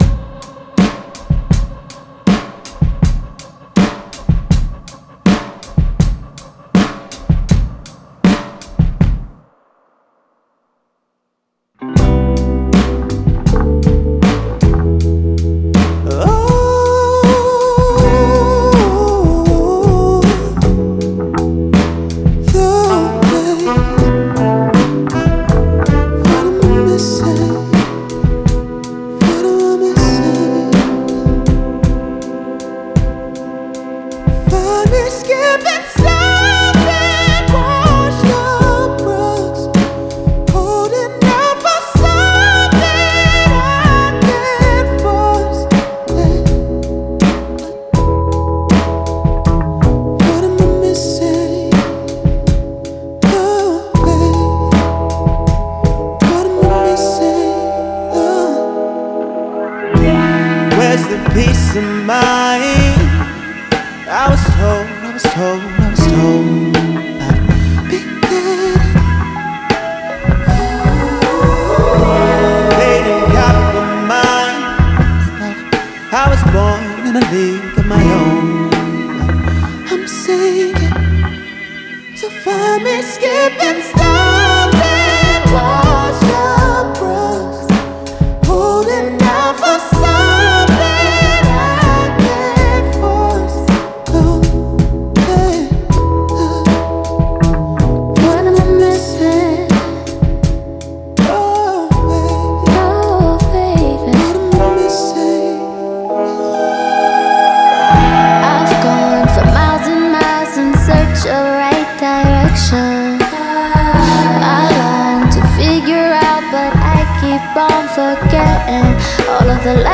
Жанр: R&B/Soul.